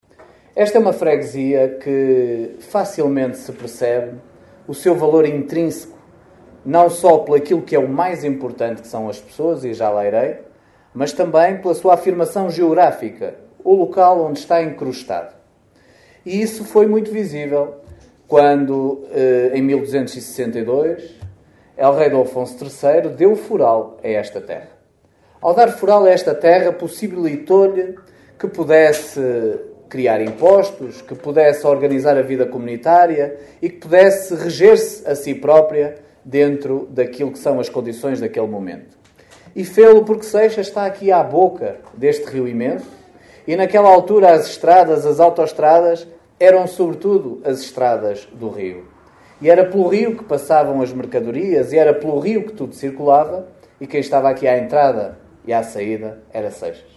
A cerimónia contou com a presença do presidente da Câmara de Caminha, Miguel Alves, que sublinhou o valor intrínseco da freguesia de Seixas valor esse que lhe valeu foral em 1262.